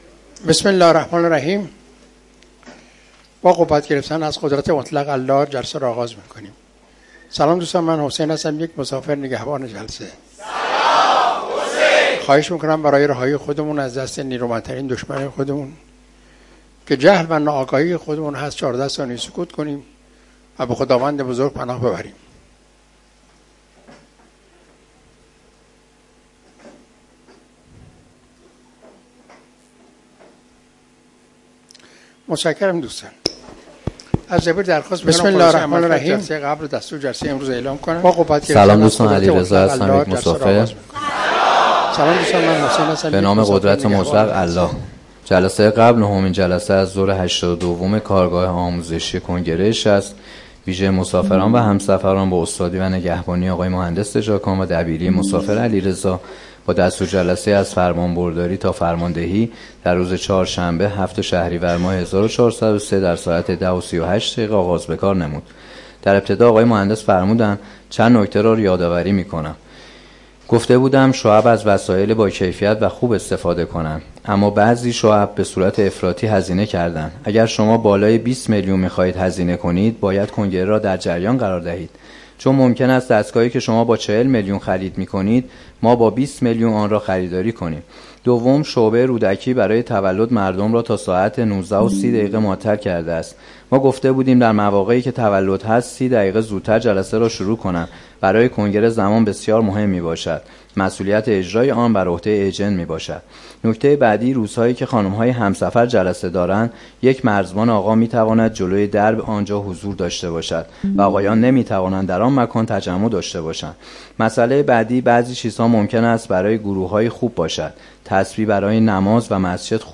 کارگاه آموزشی جهان‌بینی؛از سایت و اینستاگرام کنگره 60 چگونه استفاده می‌کنم؟ مقاله نویسی